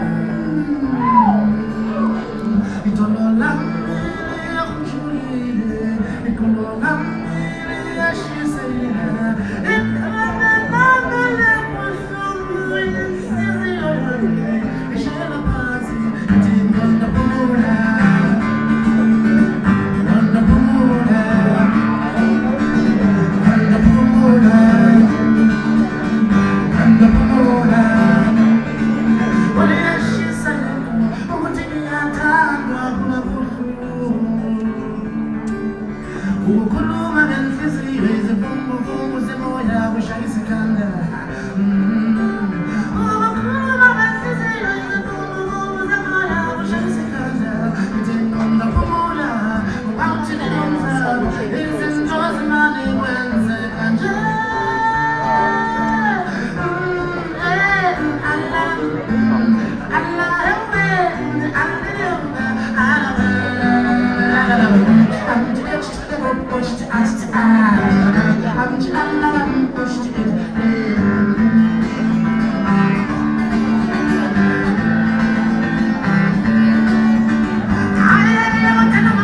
Vuvuzela - Opening Act